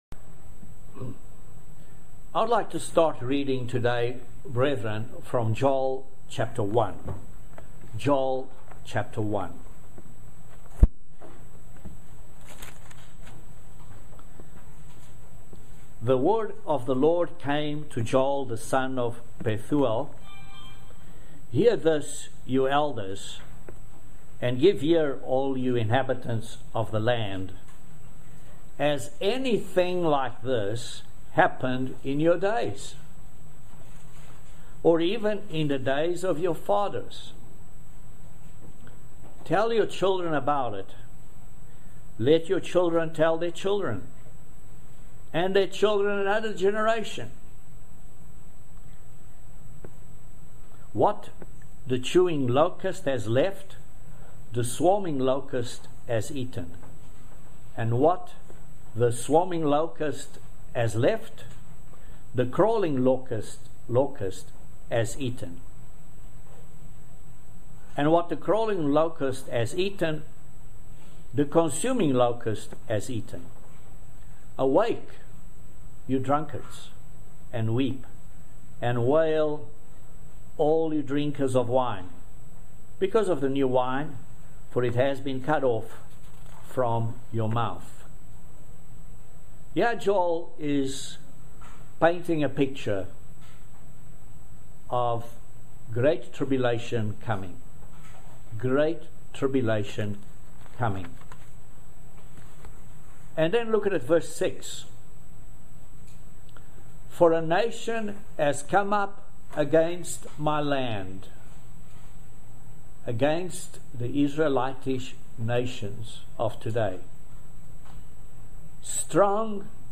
Join us for this very interesting video sermon on the subject of the Day of the Lord and the 2nd coming of Jesus Christ. Will the Church be protected from the great tribulation?